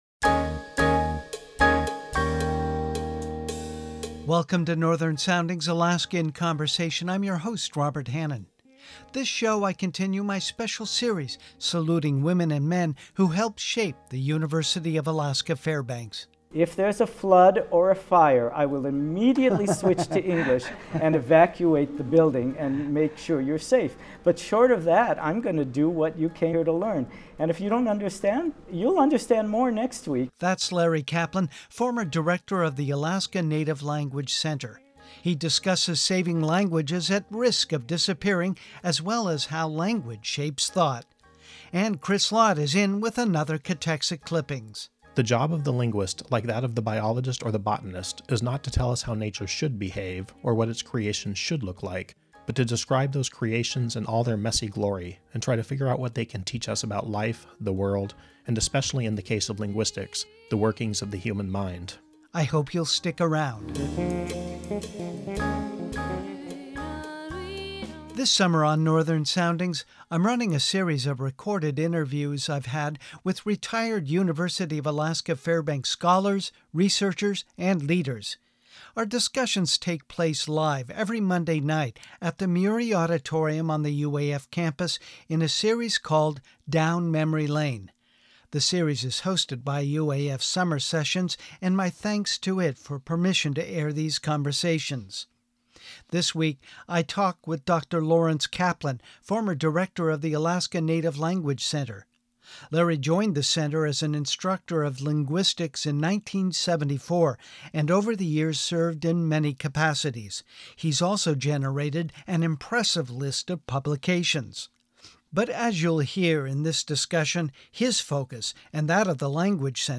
But as you’ll hear in this interview, he admits the ability to learn and speak a language is mysterious.